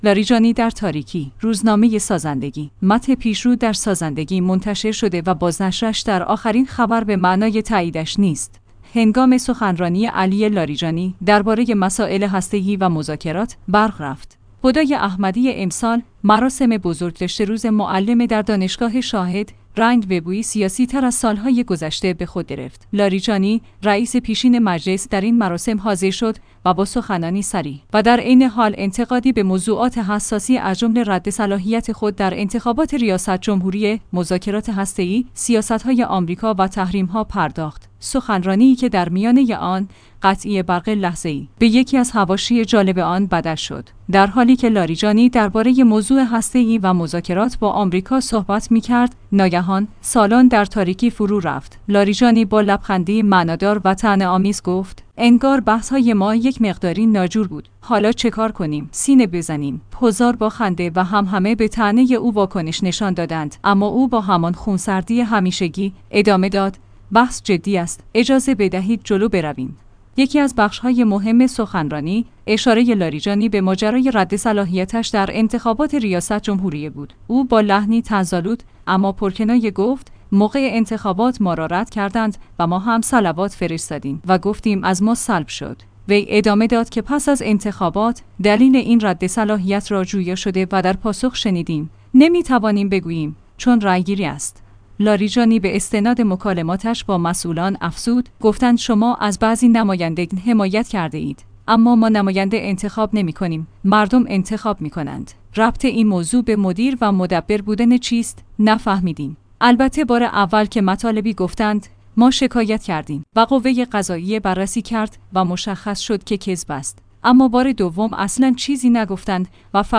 روزنامه سازندگی/متن پیش رو در سازندگی منتشر شده و بازنشرش در آخرین خبر به معنای تاییدش نیست هنگام سخنرانی “علی لاریجانی”، درباره مسائل هسته‌ای و مذاکرات، برق رفت!